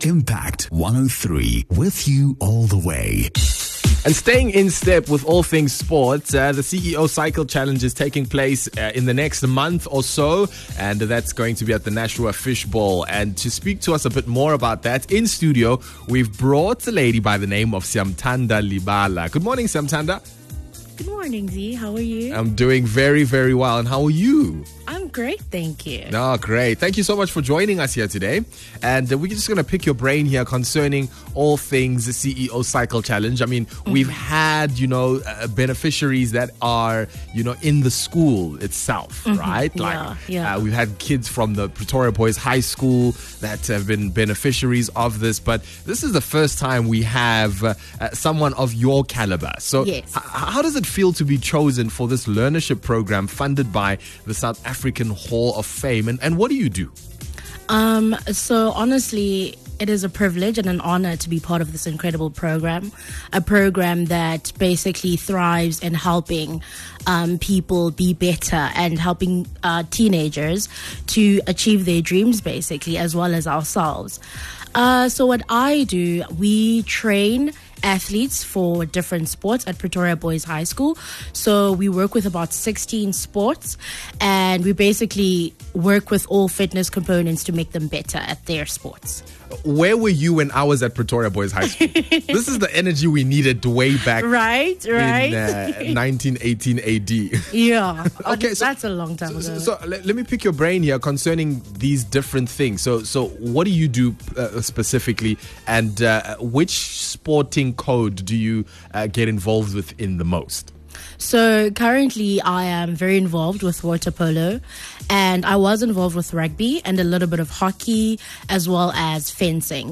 8 Aug Impact103 Morning Drive Interview